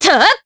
Aselica-Vox_Attack2_jp.wav